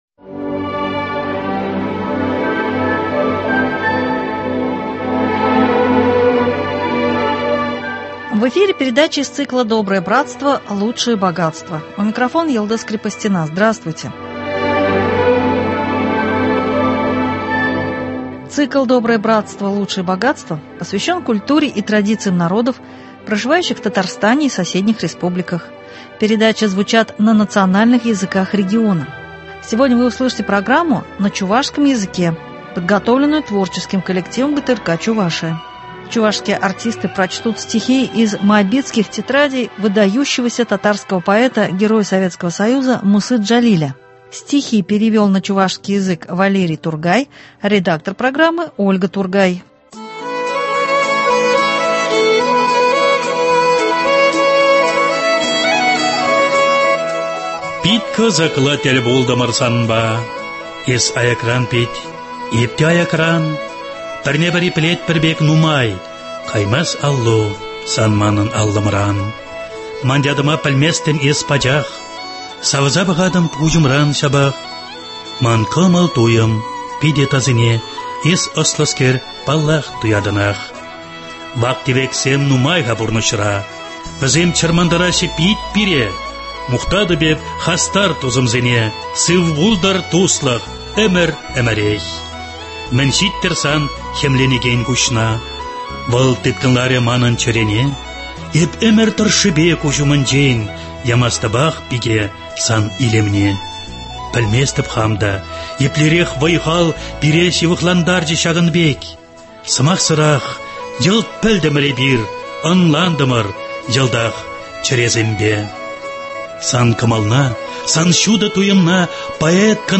Чувашские артисты прочтут стихи из Моабитских тетрадей выдающегося татарского поэта, героя Советского Союза Мусы Джалиля.